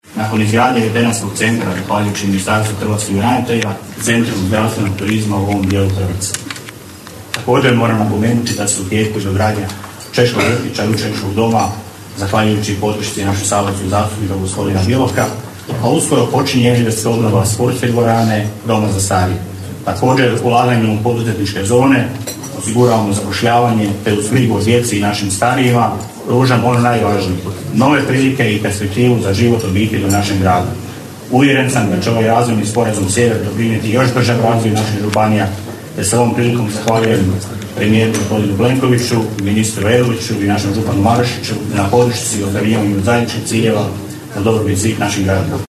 Nakon uručivanja Ugovora sastanak je nastavljen u restoranu Terasa. Uvodno se visokim uzvanicima obratio gradonačelnik Daruvara Damir Lneniček pruživši kratak uvid u realizaciju kapitalnih investicija najvećih u proteklih više od pola stoljeća na području Daruvara